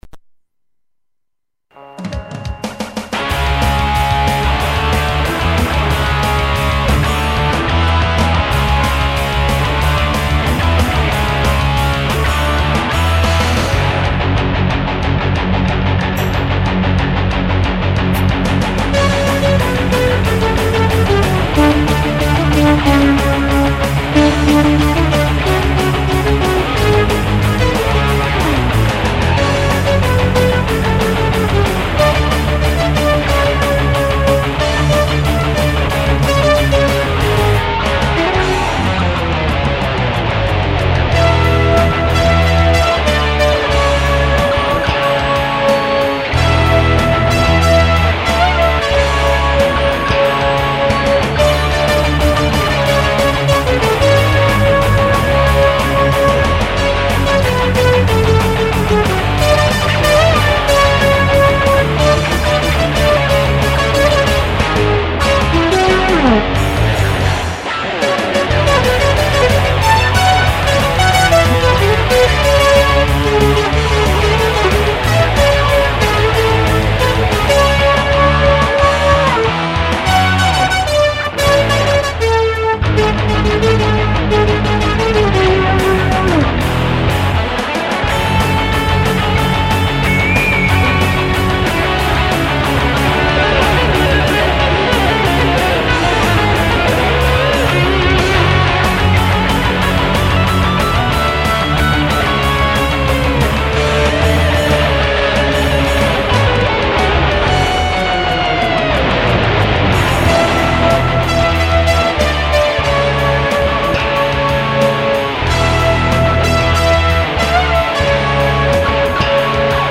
（Guitar,Bass and Programming)
下手の横好きで、一人でコソコソ演っているギターで、何となく録音した音を晒してしまいます。